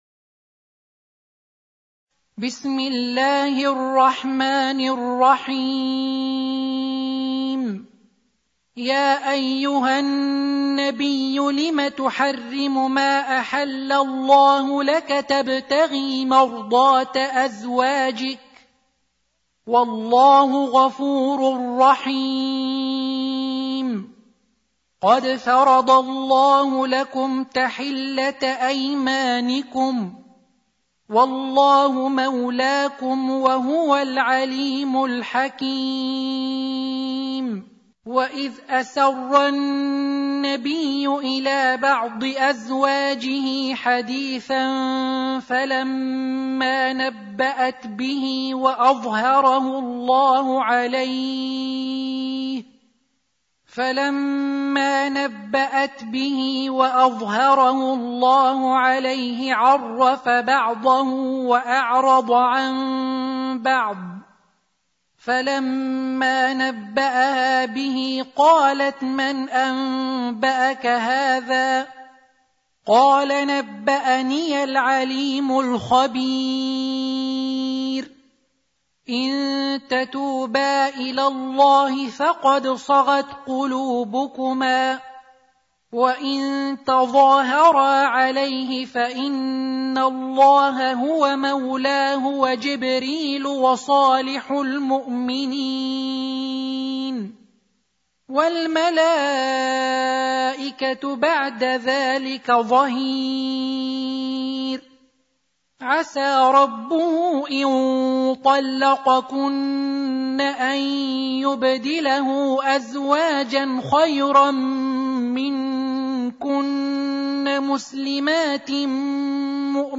Surah Sequence تتابع السورة Download Surah حمّل السورة Reciting Murattalah Audio for 66. Surah At-Tahr�m سورة التحريم N.B *Surah Includes Al-Basmalah Reciters Sequents تتابع التلاوات Reciters Repeats تكرار التلاوات